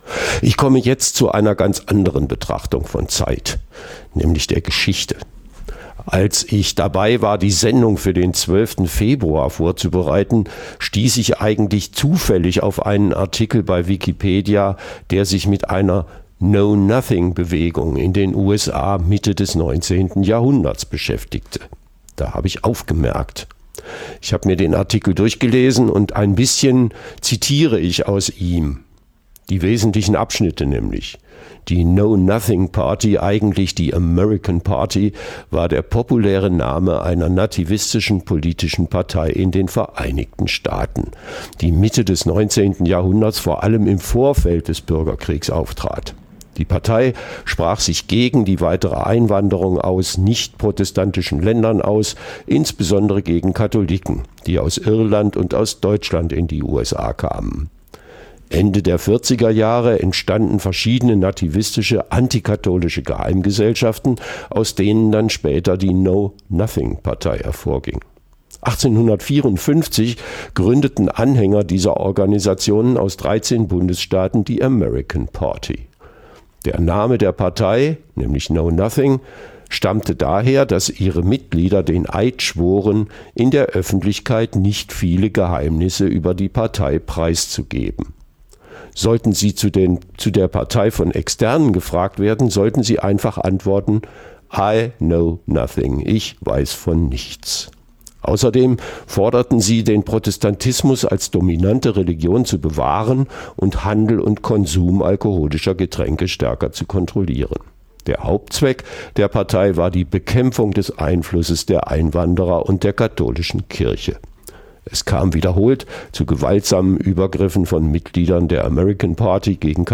Auch Trumps Geschichte - welche Bedingungen und Parallelen weist die amerikanische Geschichte auf. Ein Gespräch